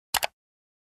Mouse Click.mp3